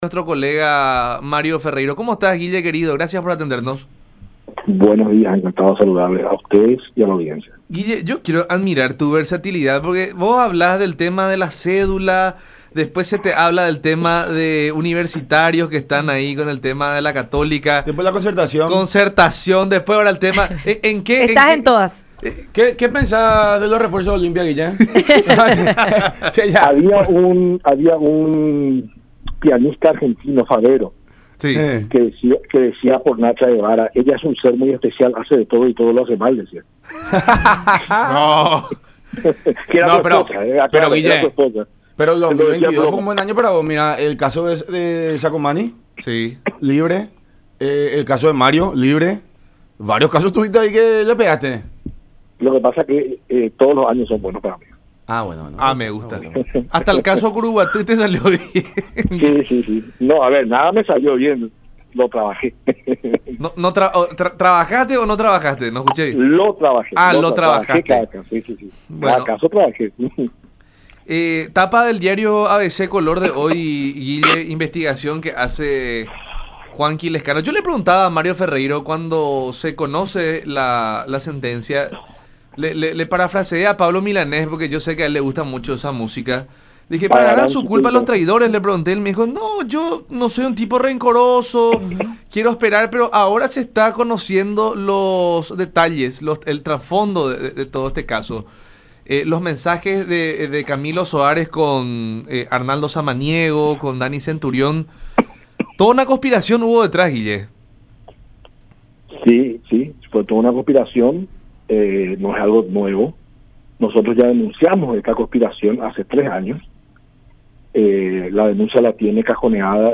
en diálogo con La Unión Hace La Fuerza por Unión TV y radio La Unión.